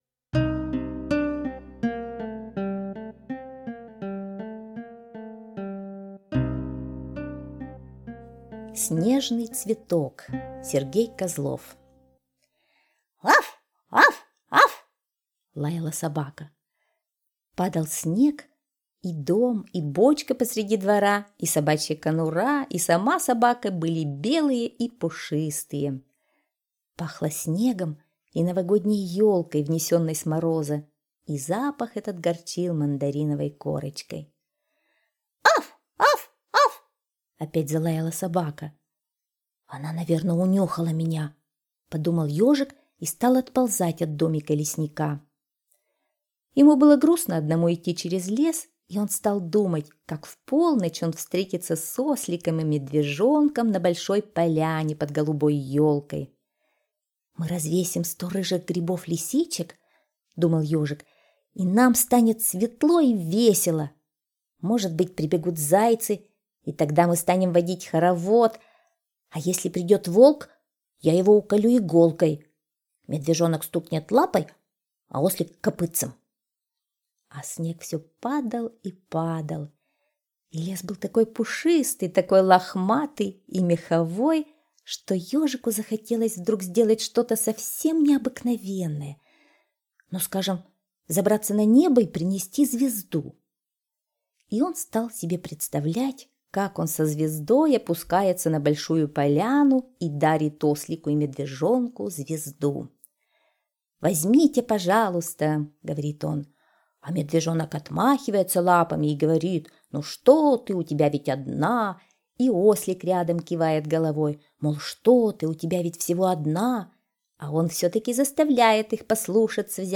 Слушайте Снежный цветок - аудиосказка Козлова С.Г. Сказка про Ежика, который в Новый год очень хотел подарить что-нибудь прекрасное своим друзьям.